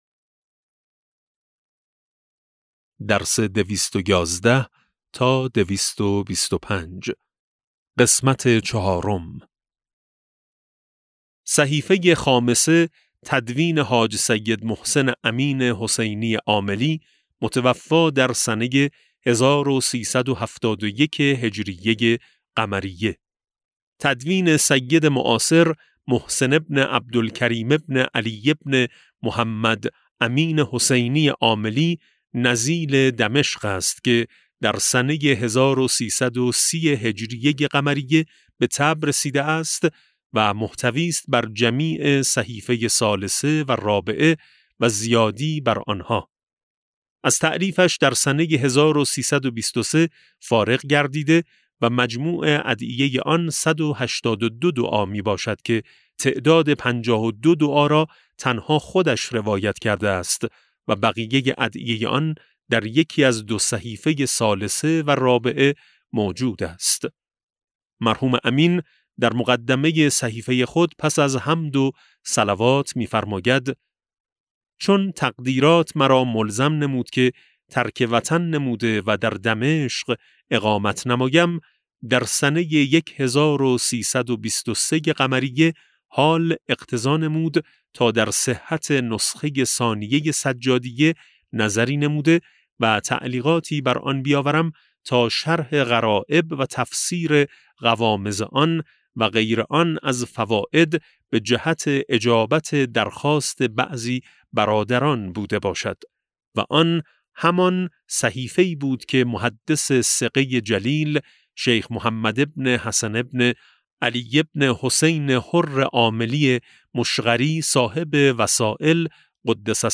کتاب صوتی امام شناسی ج15 - جلسه4